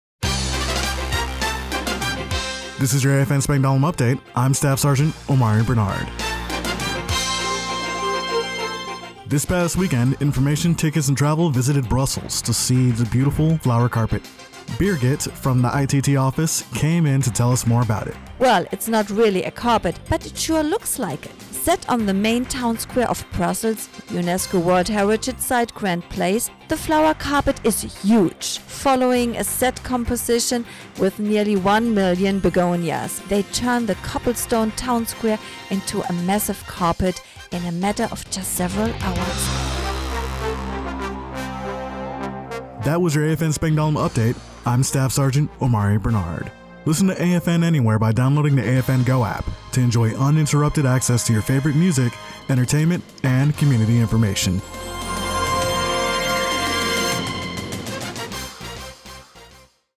The following was the radio news report for AFN Spangdahlem for August 19, 2024.